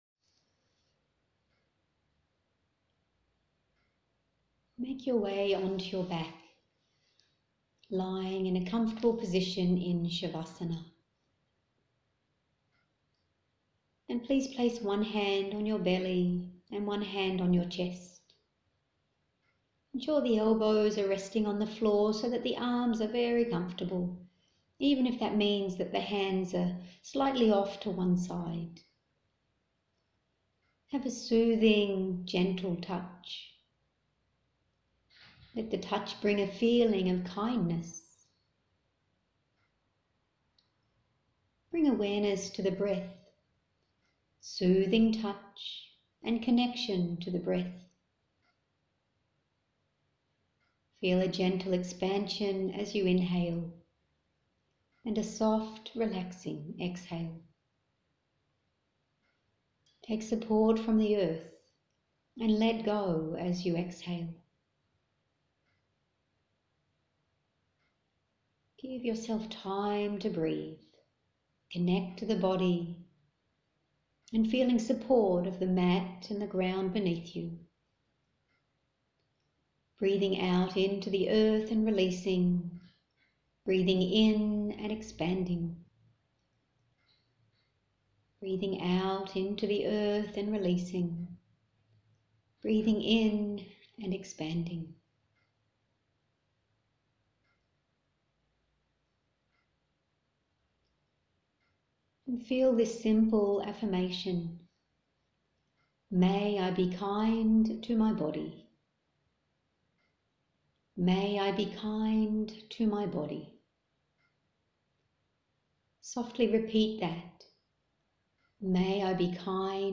Thankyou For Attending the “Yoga for Anxiety” Workshop